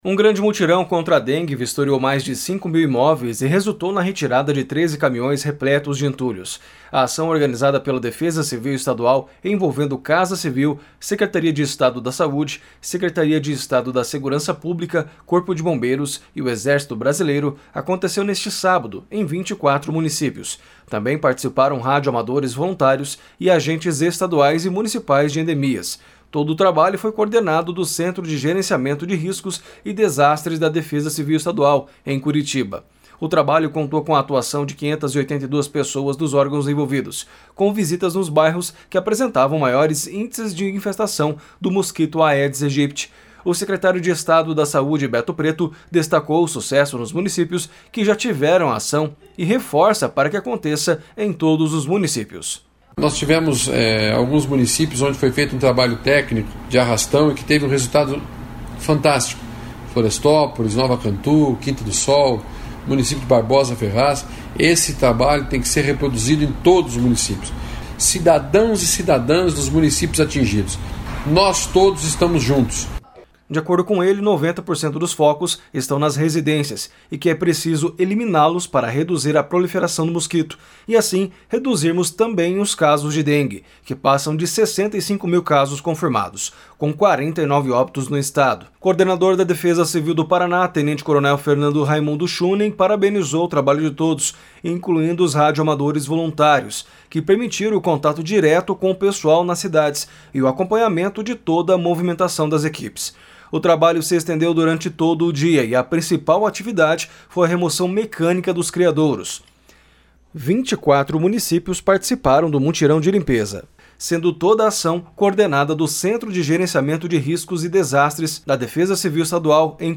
O secretário de Estado de Saúde, Beto Preto, destacou o sucesso nos municípios que já tiveram a ação e reforça para que aconteça em todos os municípios //SONORA BETO PRETO// De acordo com ele, 90% dos focos estão nas residências e que é preciso eliminá-los para reduzir a proliferação do mosquito, e assim reduzirmos também os casos de dengue, que passam de 65 mil casos confirmados, com 49 óbitos no Estado.